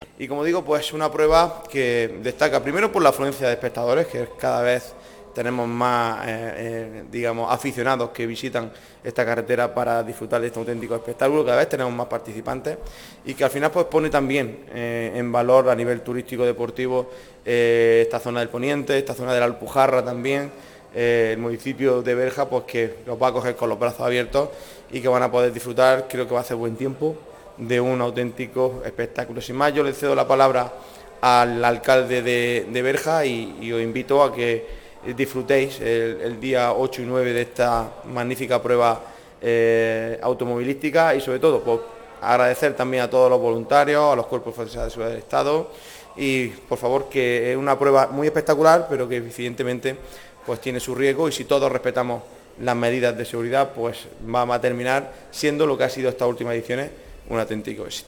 06-11_subida_berja_diputado.mp3